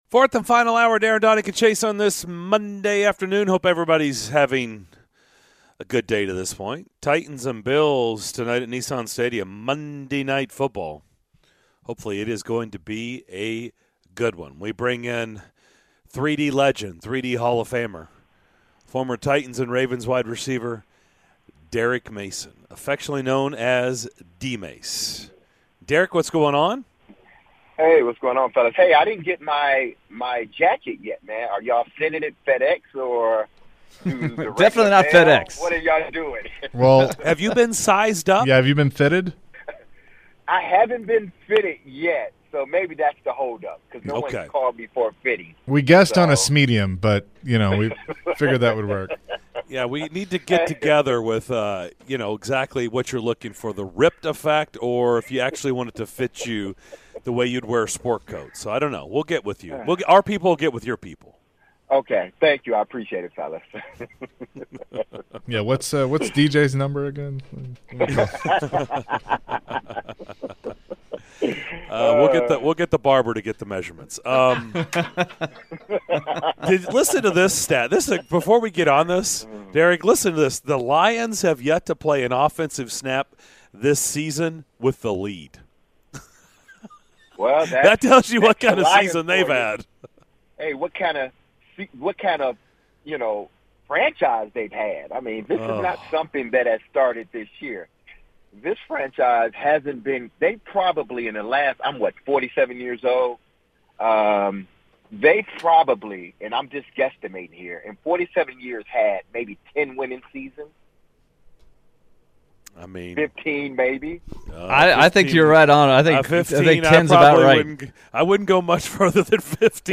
Legendary Titans Receiver Derrick Mason joined the DDC to breakdown the matchups in tonight's Bills-Titans game!